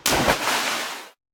drop sound.
drop.ogg